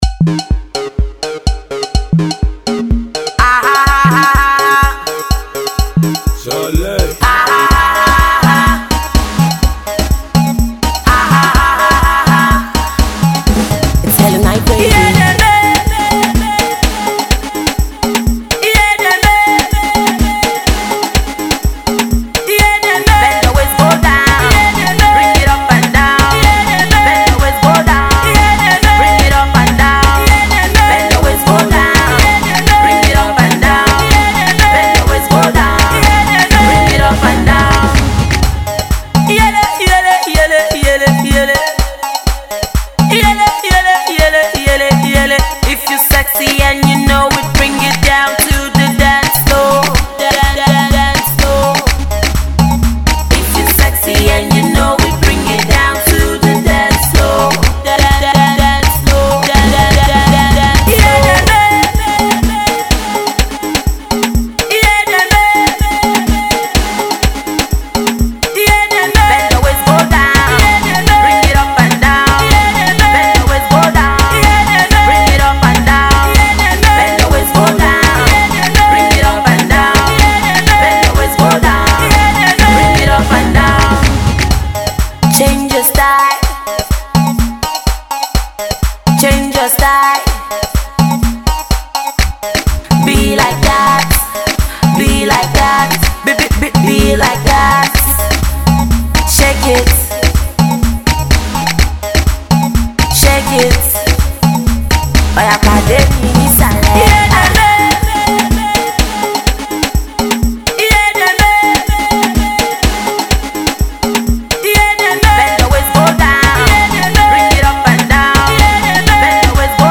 Pop
dance song